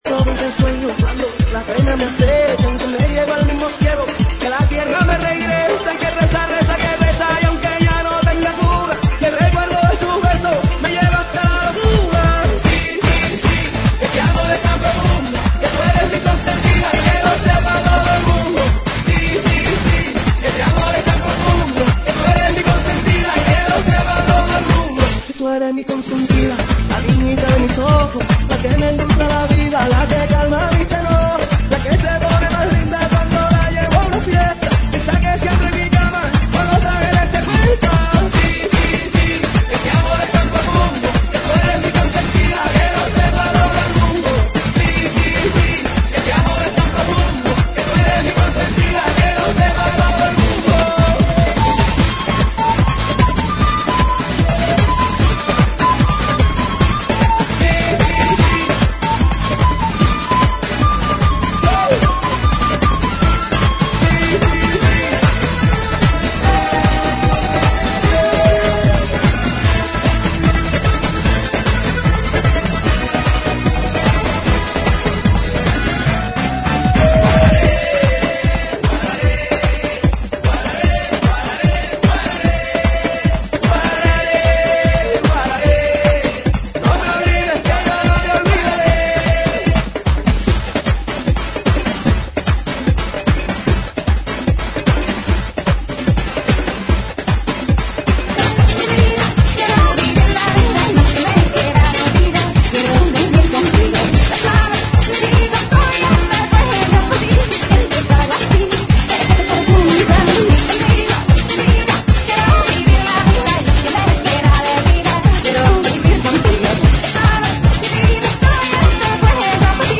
GENERO: LATINO – RADIO
AEROBICS (STEP-HILOW)